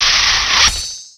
Cri de Scalproie dans Pokémon X et Y.